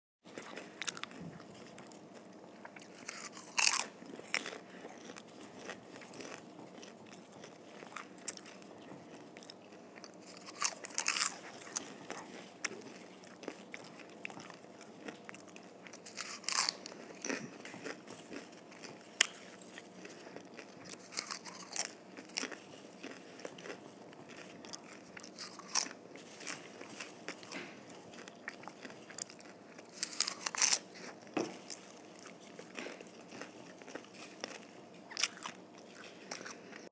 Eating Popcorn
eating-popcorn.m4a